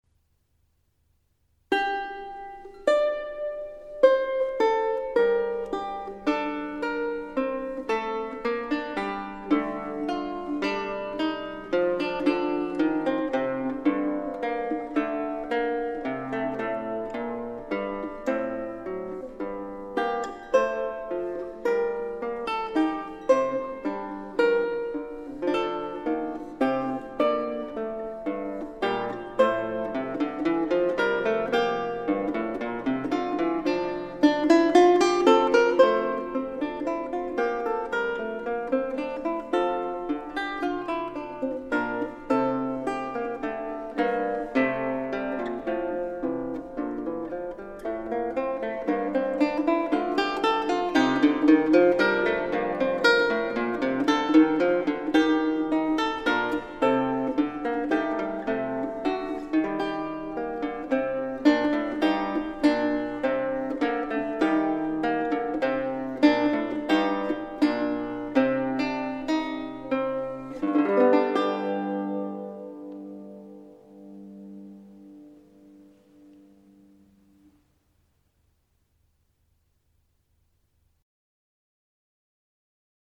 lyutnevaya_muzyka_srednevekov_ya_fantasia.mp3